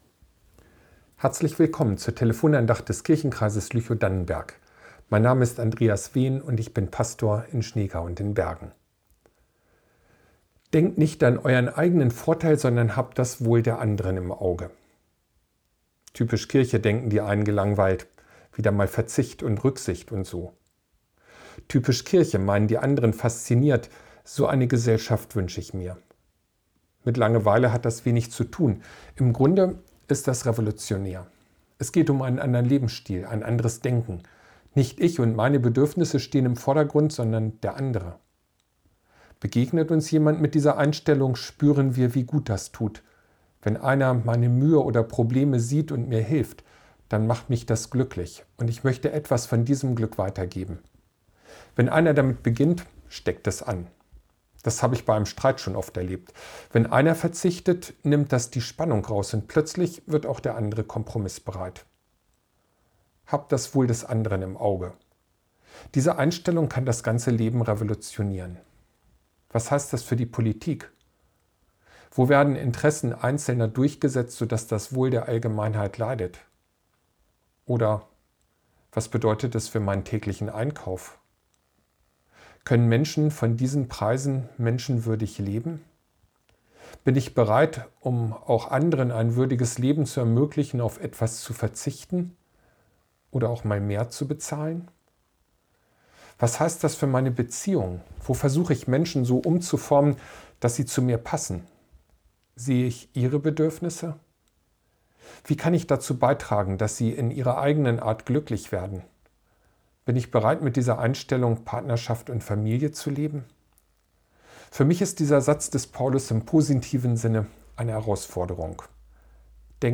Das Wohl des anderen ~ Telefon-Andachten des ev.-luth. Kirchenkreises Lüchow-Dannenberg Podcast